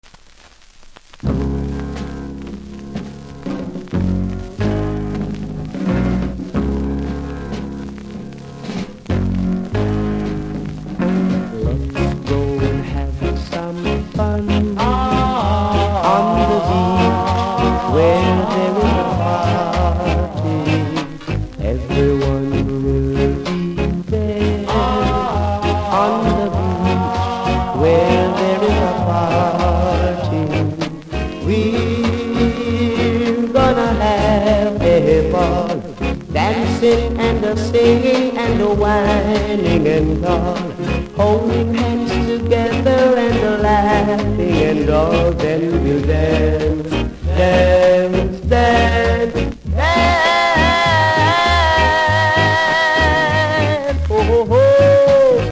キズ多めノイズもそれなりに感じますので試聴で確認下さい。